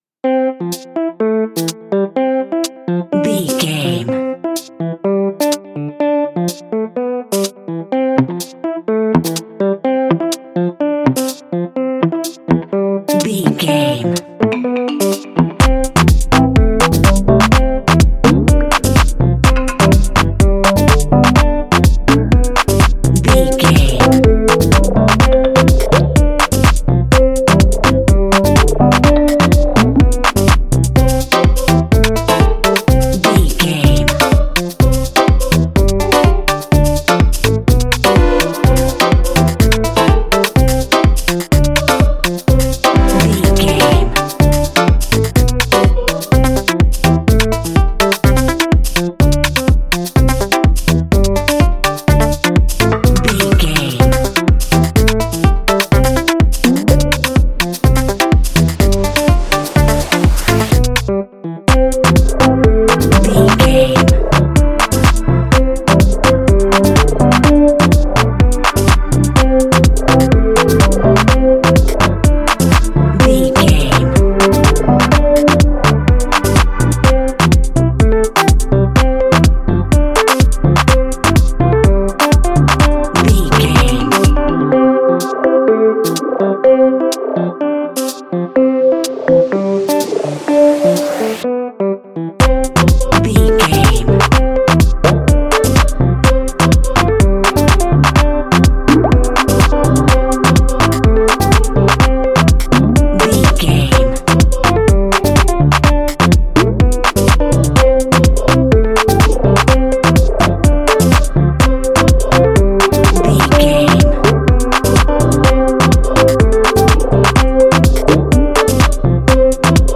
Ionian/Major
bright
energetic
festive
Rhythmic